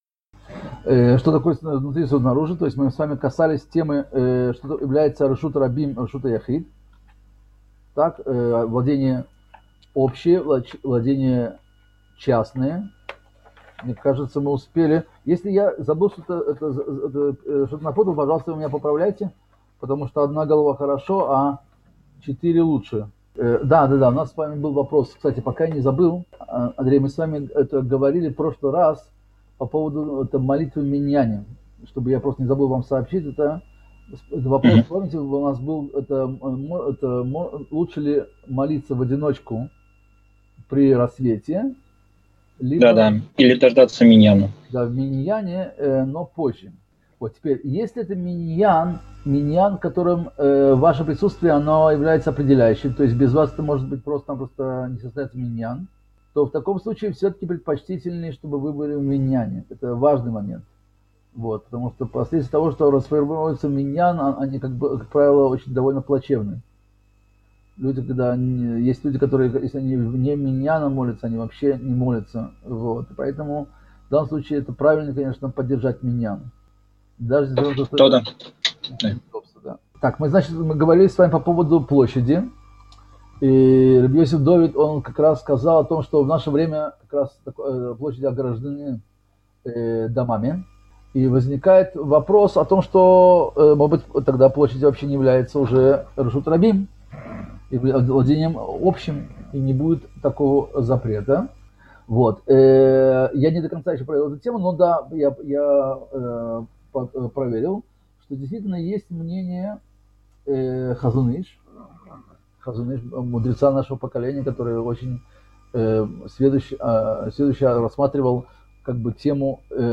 Цикл уроков по изучению мишны Шаббат